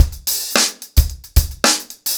TupidCow-110BPM.43.wav